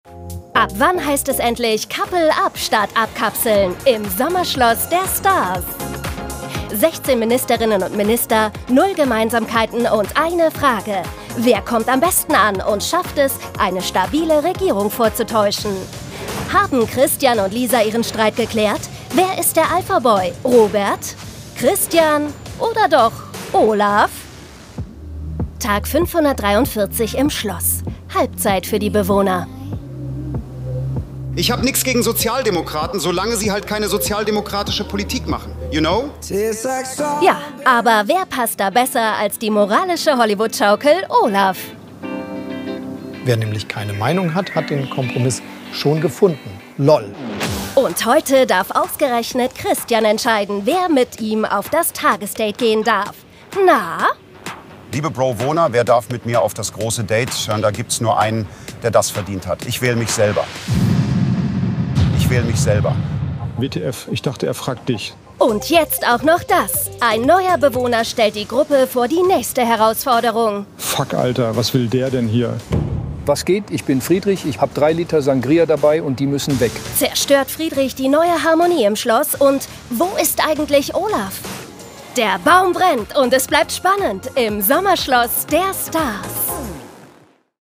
sehr variabel, markant, hell, fein, zart, plakativ
Jung (18-30)
Commercial (Werbung)